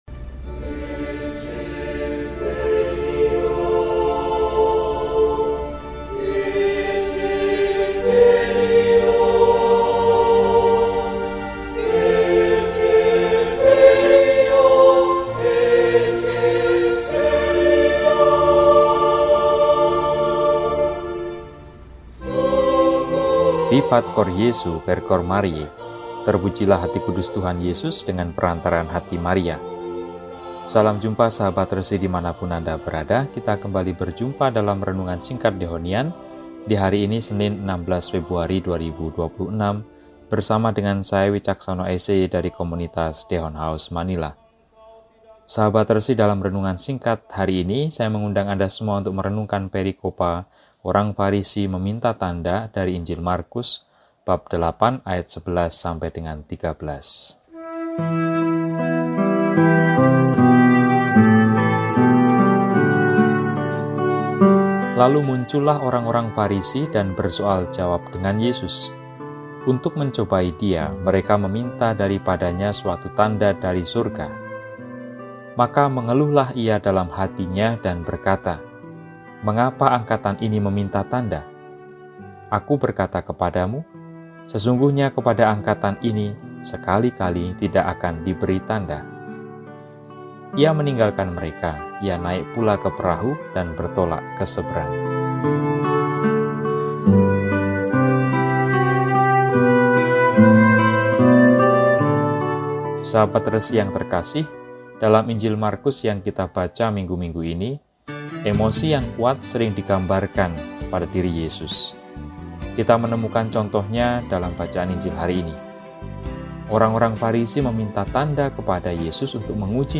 Senin, 16 Februari 2026 – Hari Biasa Pekan VI – RESI (Renungan Singkat) DEHONIAN